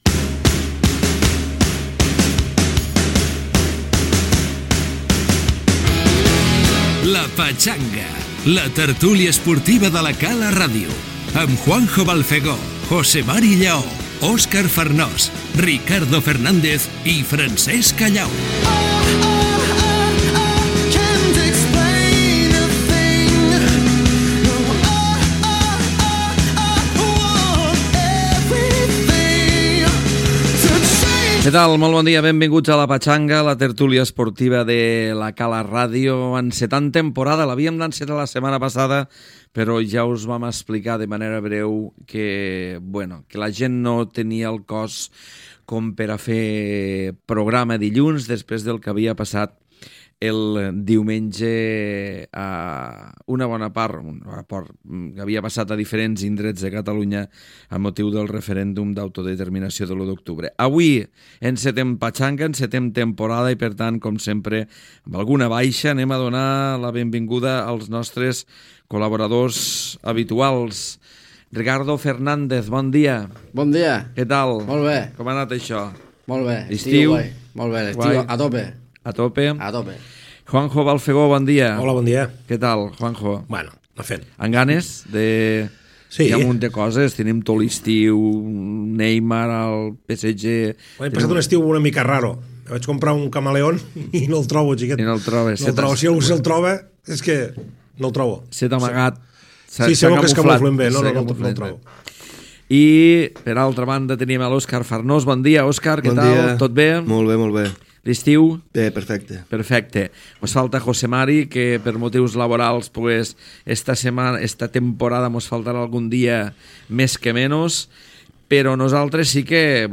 Careta del programa, esment al referèndum de l'1 d'octubre de 2017, presentació dels integrants de la tertúlia esportiva i repàs a l'actualitat futbolística
Esportiu